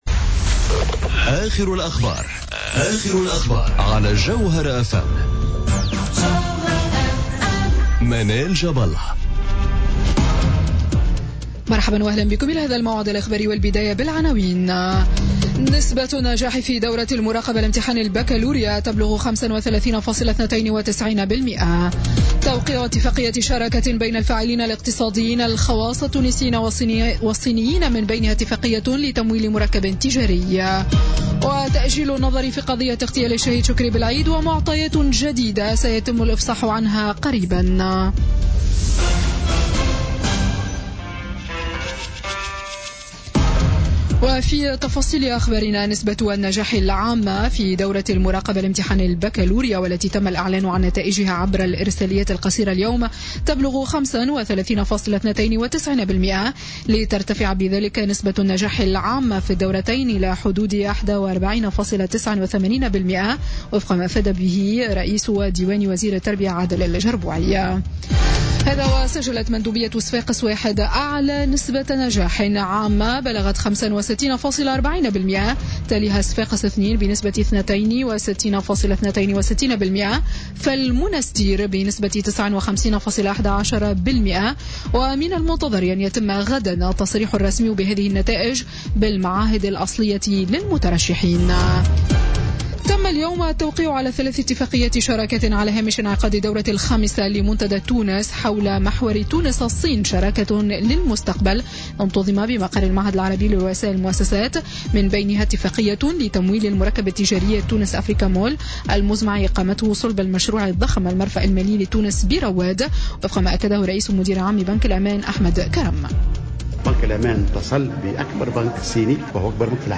نشرة أخبار السابعة مساء ليوم الجمعة 07 جويلية 2017